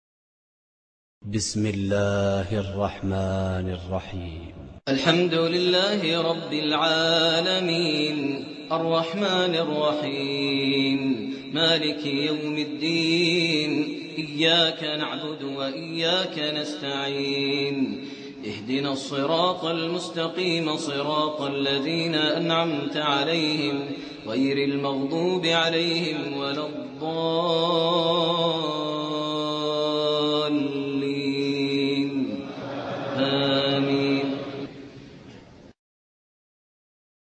سورة الفاتحة بصوت قراء